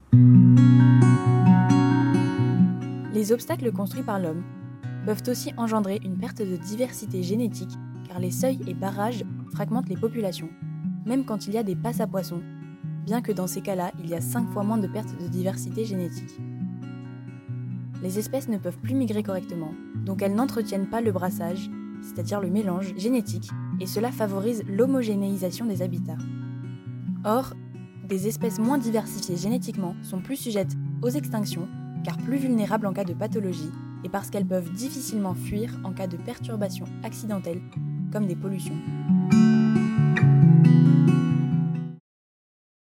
Intervenante